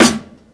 Snares
pow_snr.wav